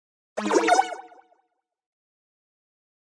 游戏音效 " FX170
描述：爆炸哔哔踢游戏gameound点击levelUp冒险哔哔sfx应用程序启动点击
Tag: 爆炸 单击 冒险 游戏 应用程序 点击 的LevelUp 启动 gamesound 哔哔声 SFX